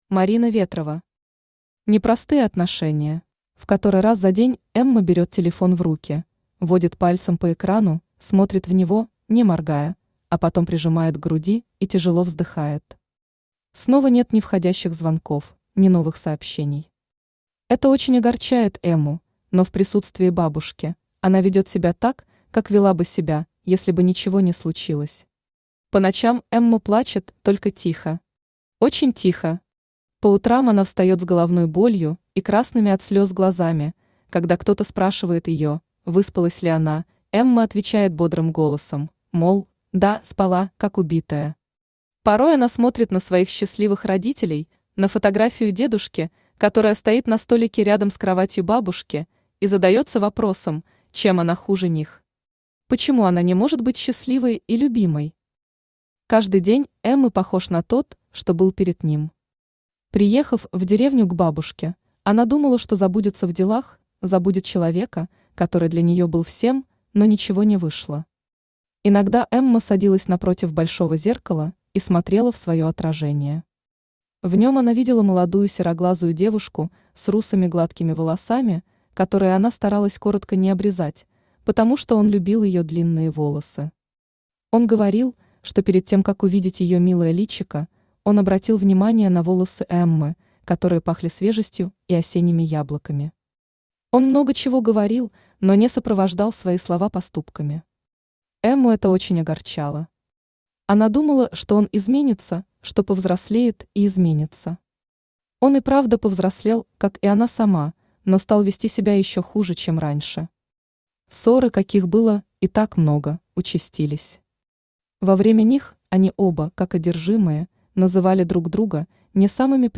Aудиокнига Непростые отношения Автор Марина Ветрова Читает аудиокнигу Авточтец ЛитРес.